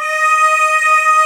Index of /90_sSampleCDs/AKAI S6000 CD-ROM - Volume 1/VOCAL_ORGAN/BIG_CHOIR
VOCAL AH EB3.WAV